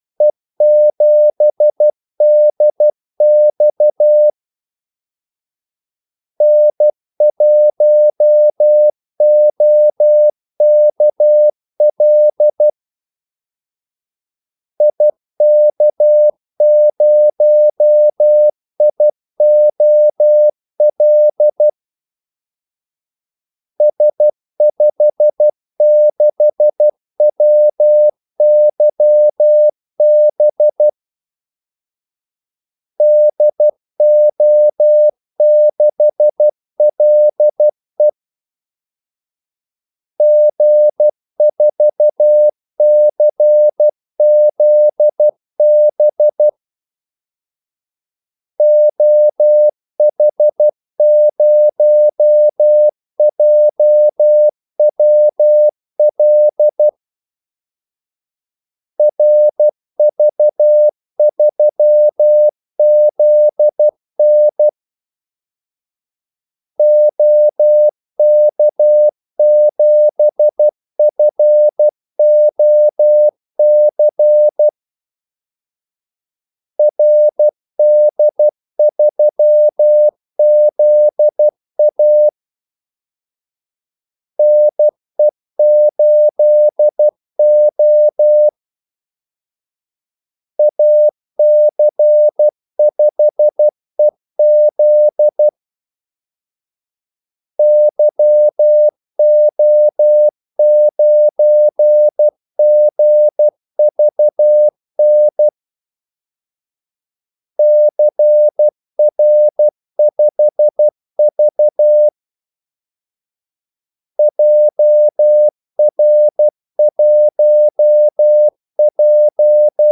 Kaldesignaler 12 wpm | CW med Gnister
2 ekstra ordmellemrum mellem kaldesignalerne
Callsigns-12wpm.mp3